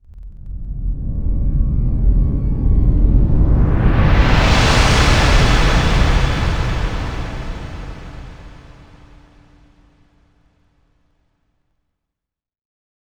DebrisHum.wav